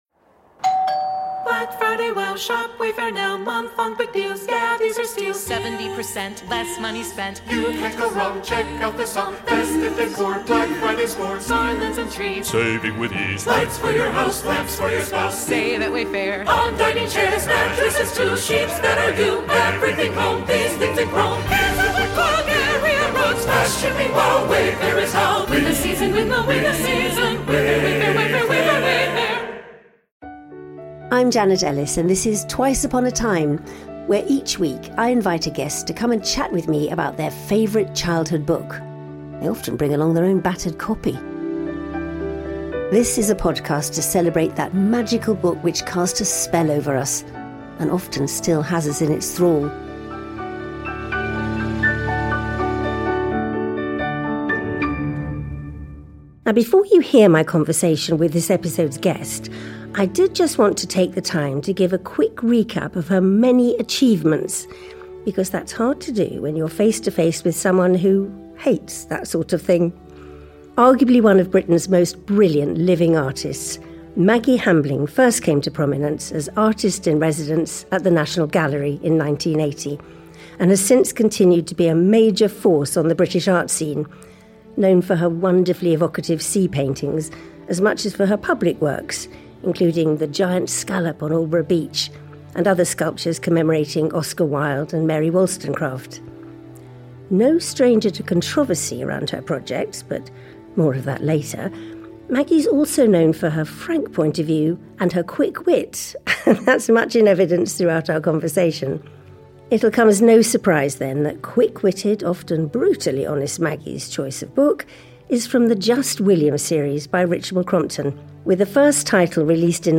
In this special episode with the brilliant British artist Maggi Hambling, released on Maggi's birthday, host Janet Ellis finds out about Maggi's love for all things 'Just William'.